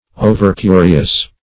overcurious.mp3